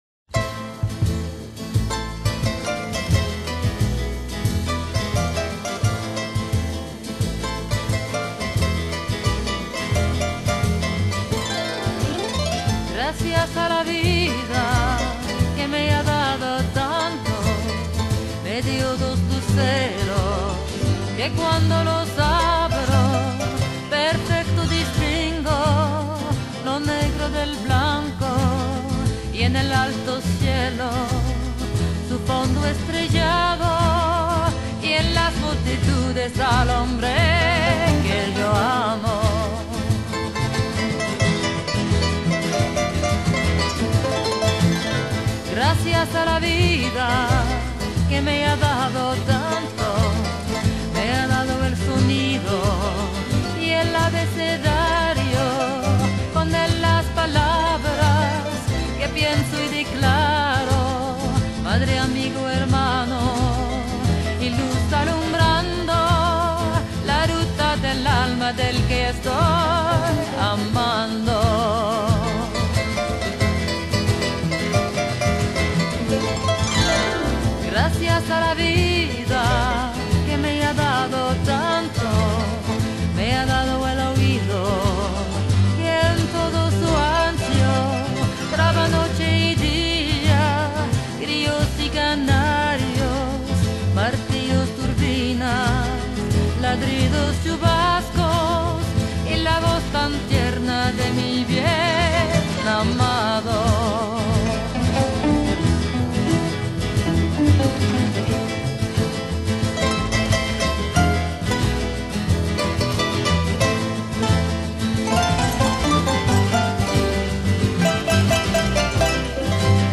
Genre: Folk Rock, Folk, Singer/Songwriter